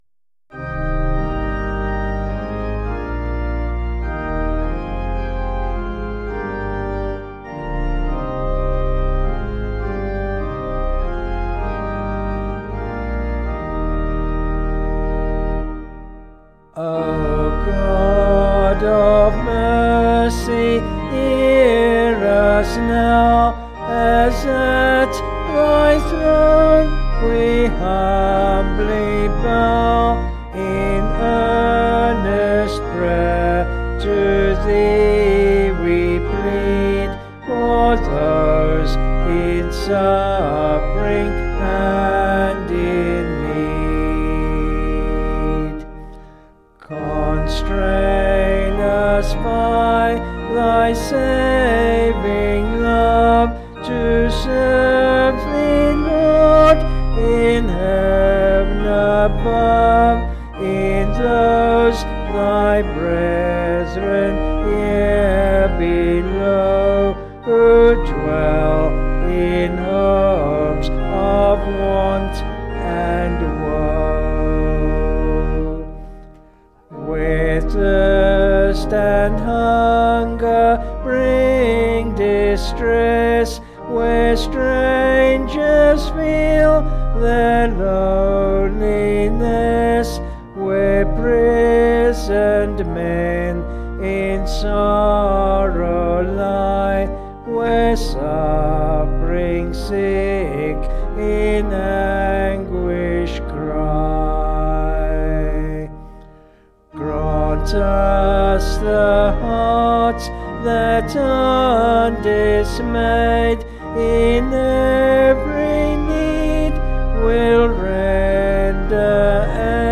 Vocals and Organ   264.5kb Sung Lyrics